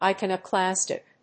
音節i・con・o・clas・tic 発音記号・読み方
/ɑɪkὰnəklˈæstɪk(米国英語)/